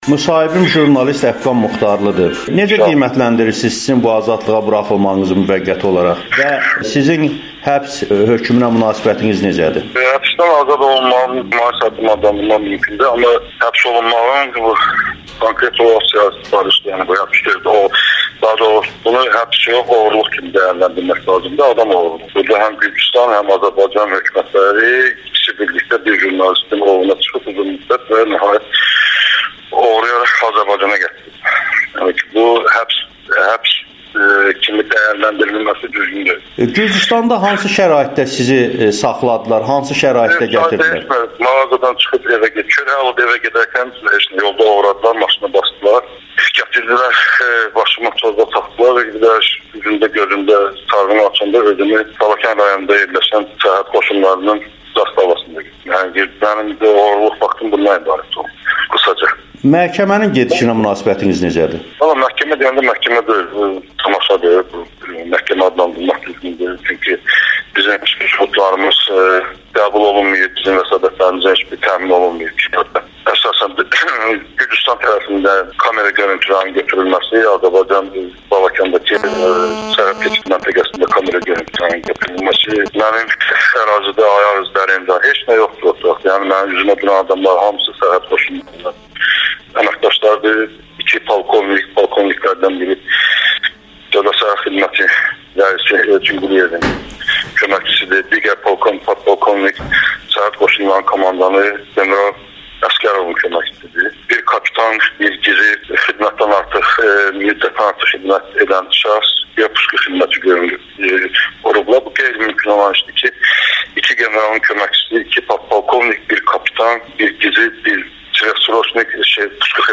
Həbsdən qısa müddətə buraxılmış jurnalist Amerikanın Səsinə müsahibə verib